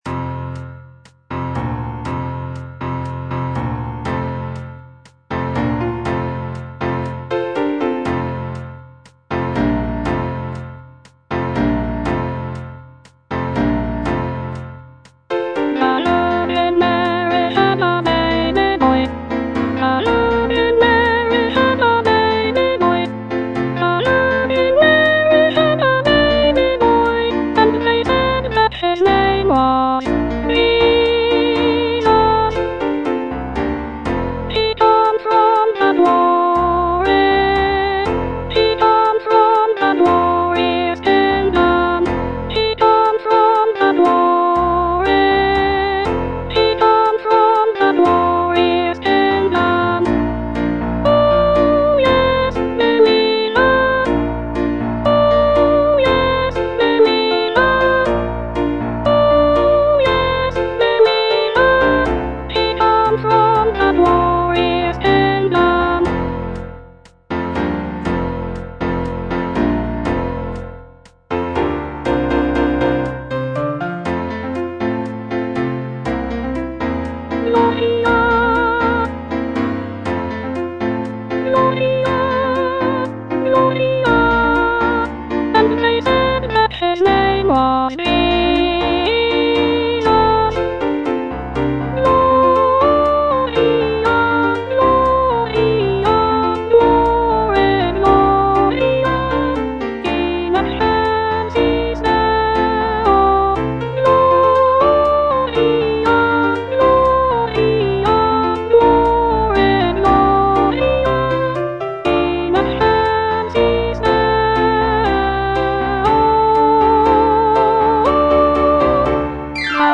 Soprano I (Voice with metronome)
vibrant and energetic arrangement
" set to a lively calypso rhythm.
incorporating Caribbean influences and infectious rhythms.